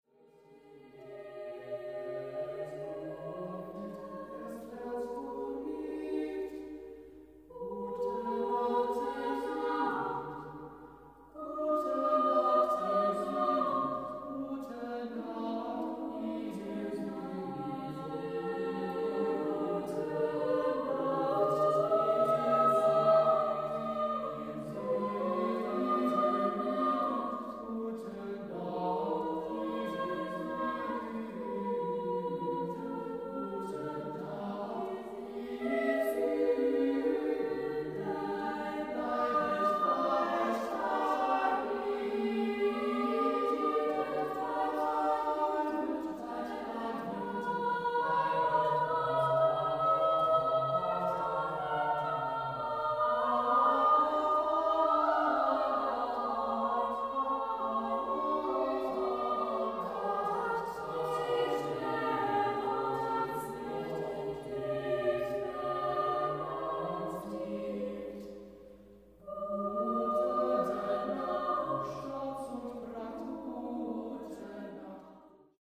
the amazing choral soundscape
fascinating sound picture, the wordless
Choral, Classical